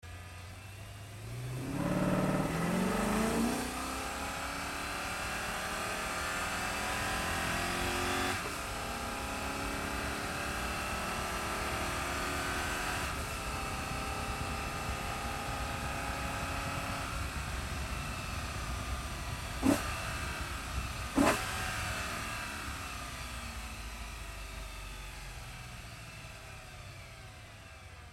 Звуки разгона машины
Звук разгона
Шум разгона